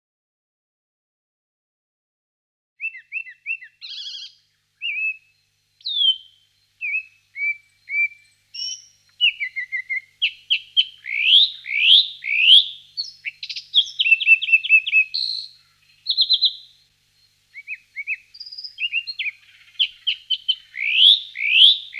Звуки природы
Пение пернатых